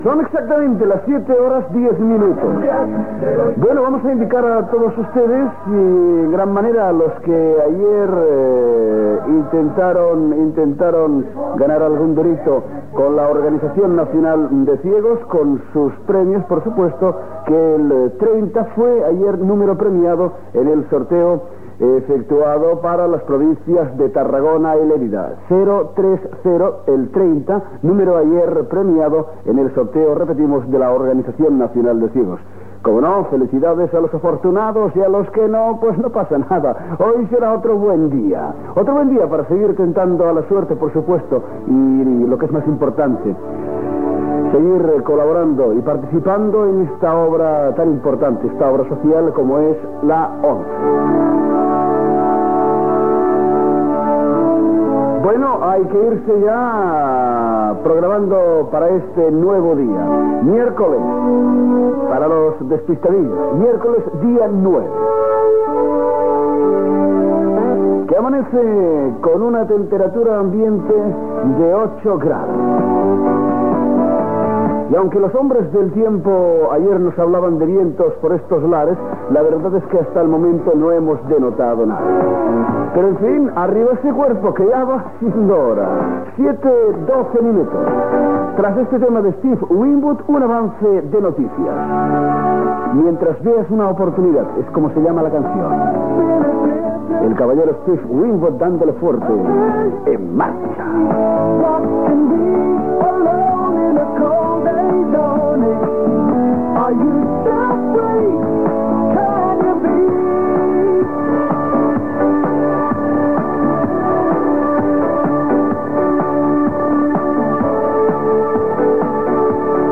Info-entreteniment
FM
Notícies presentades per Carles Francino.